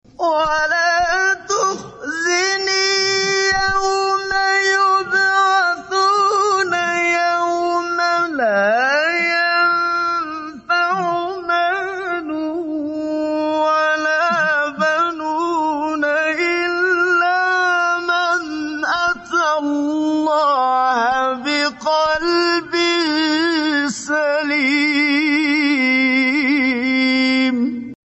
ماتيسر من سورة(الشعراء) بصوت الشيخ